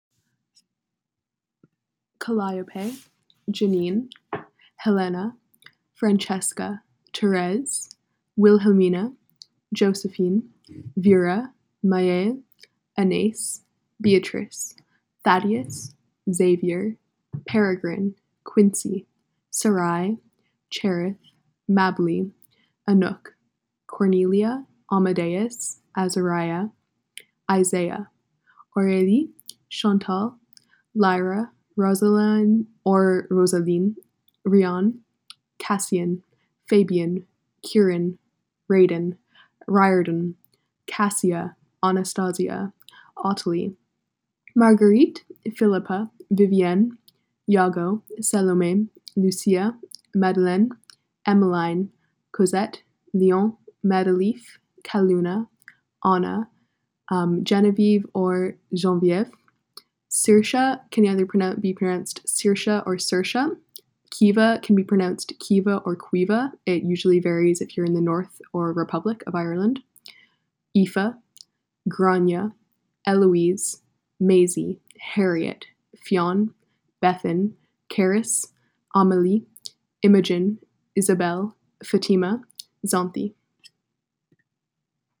For reference: I live in an Anglophone part of [name_f]Canada[/name_f], speak [name_u]French[/name_u], and am of immediate Irish descent.
this is the list of names i pronounced: